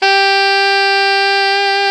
Index of /90_sSampleCDs/Roland L-CDX-03 Disk 1/SAX_Alto Tube/SAX_Alto ff Tube
SAX ALTOFF0C.wav